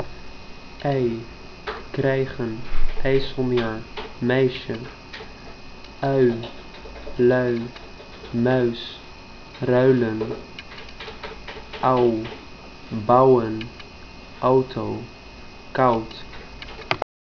diftongen.wav